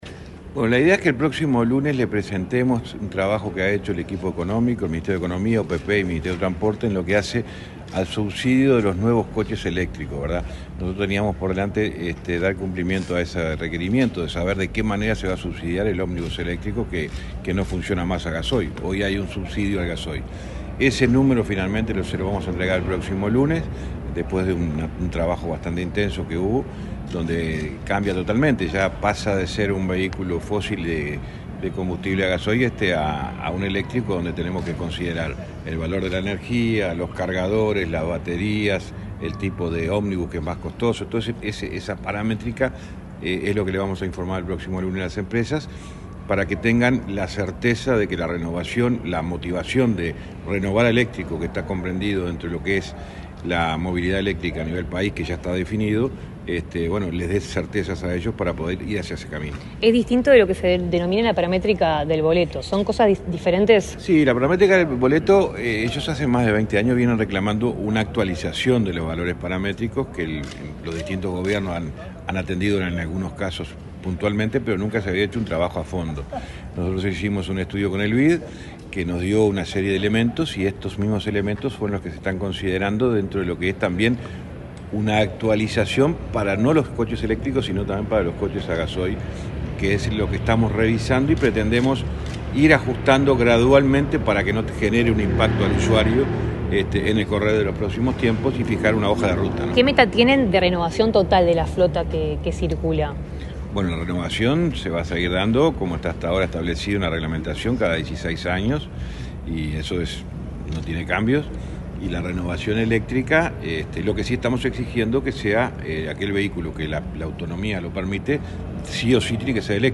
Declaraciones del ministro de Transporte, José Luis Falero
Luego de la recorrida, el ministro de Transporte, José Luis Falero, dialogó con la prensa sobre el transporte de pasajeros.